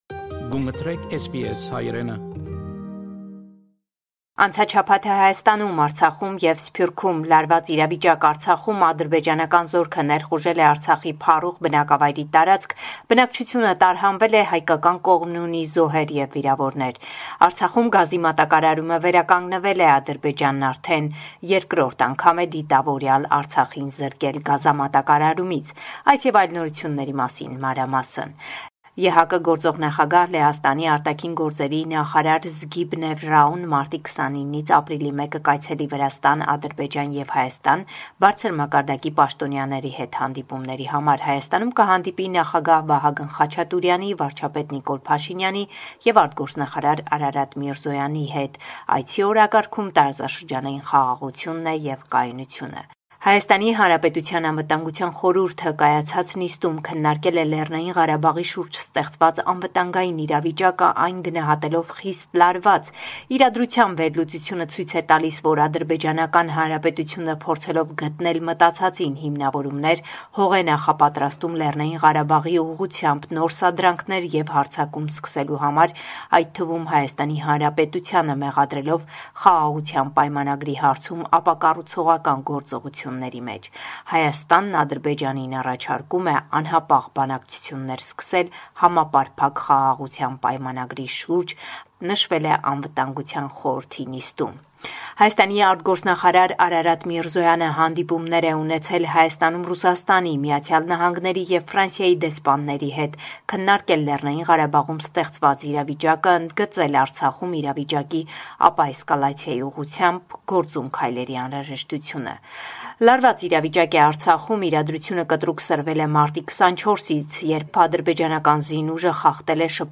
Latest News from Armenia – 29 March 2022